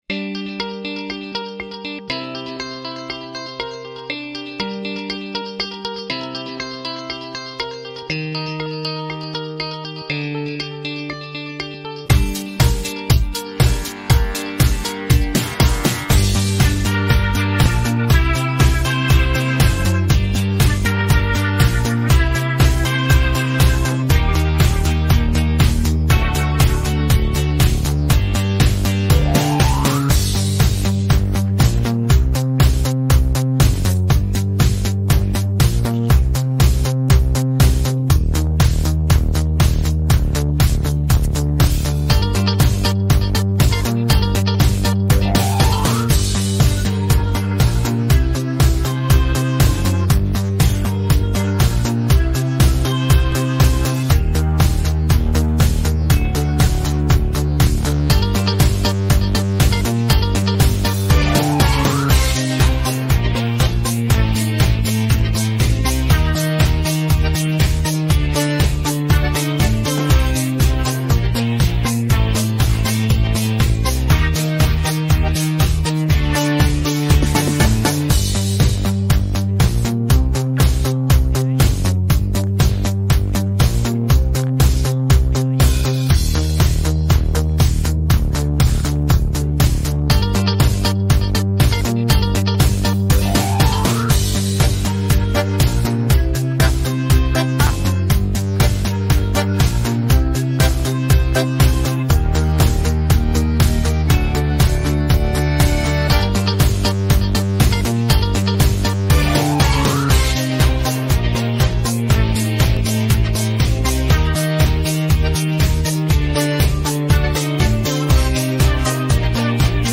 dance-pop караоке 16